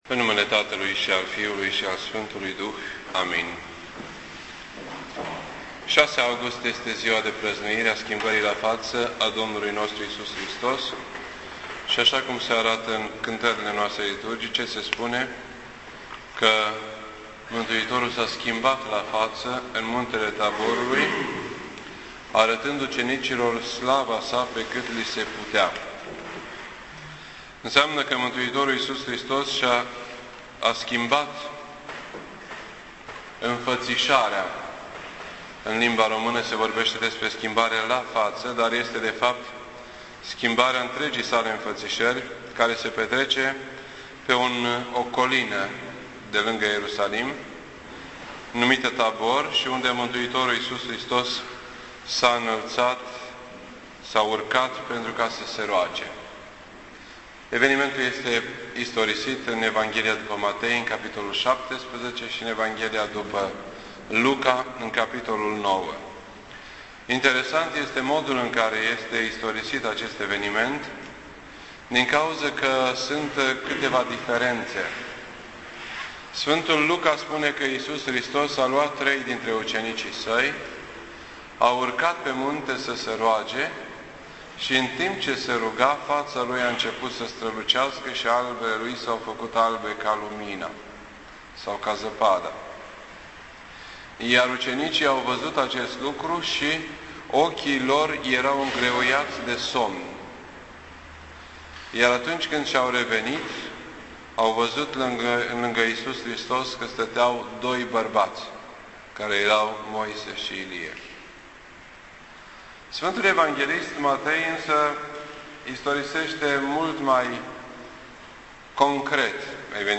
This entry was posted on Friday, August 6th, 2010 at 9:06 PM and is filed under Predici ortodoxe in format audio.